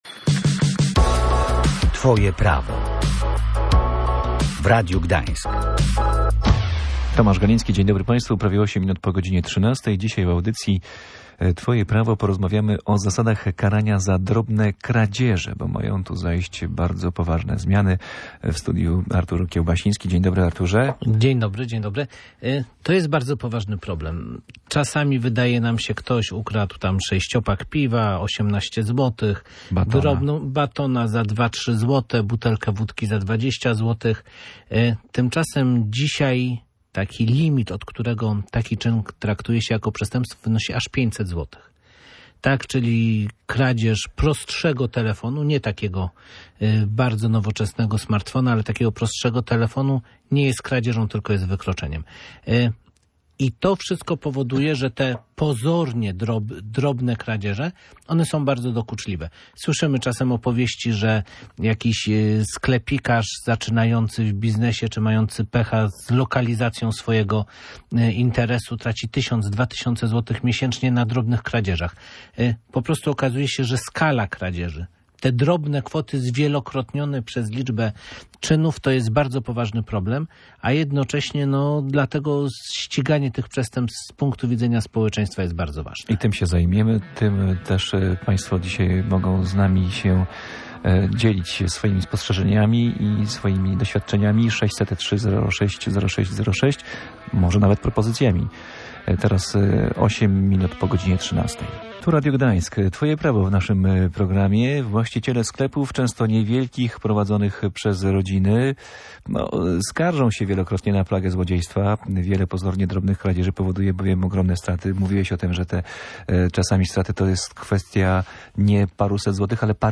W programie Twoje Prawo rozmawialiśmy o zasadach karania za drobne kradzieże. W tym zakresie mają zajść bardzo poważne zmiany.